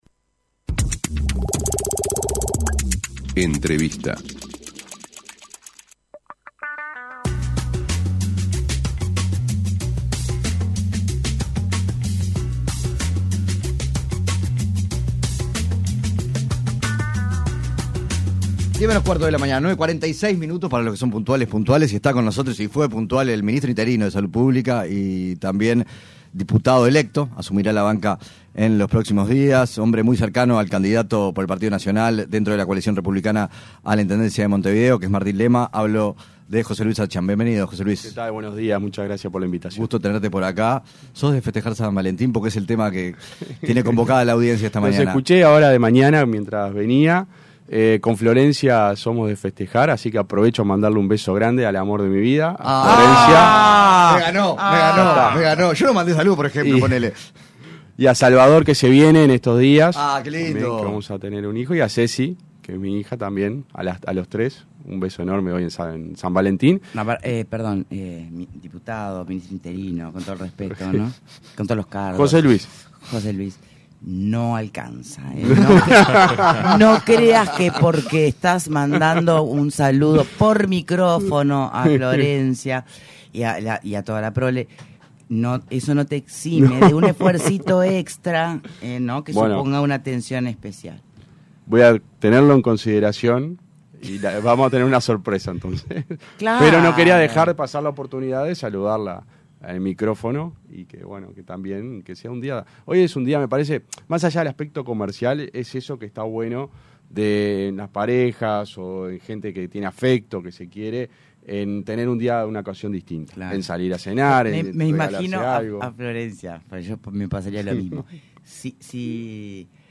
Entrevista a José Luis Satdjian (ministro interino de Salud Pública)